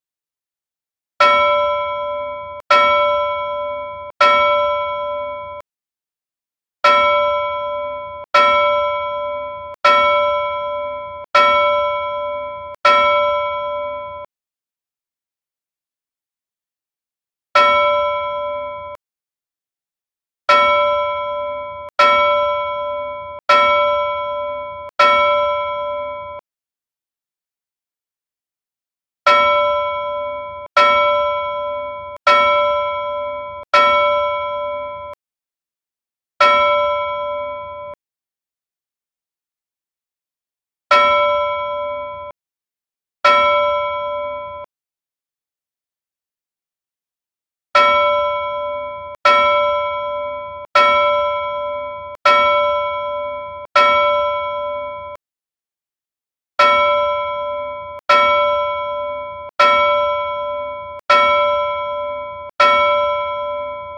Bell-Tolls_0.mp3